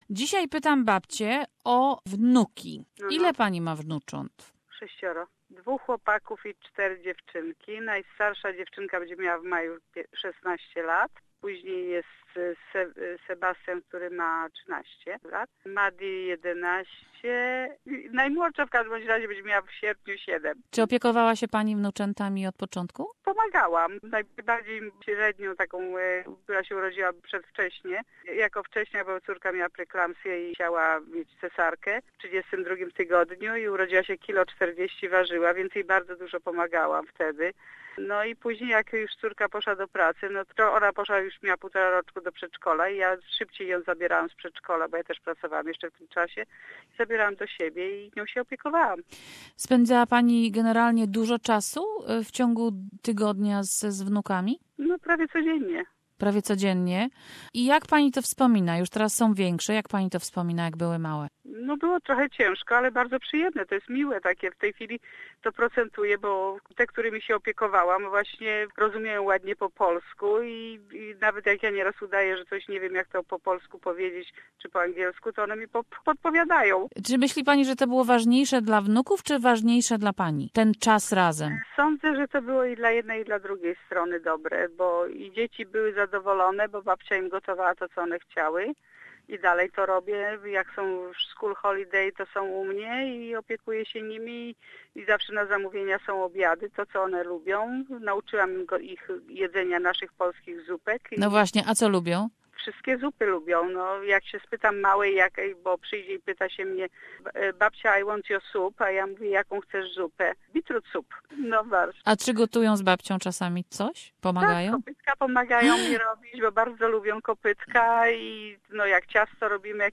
Do grandparents give grandchildren more than parents? Four grandmothers talk of their relationships with their beloved grandkids.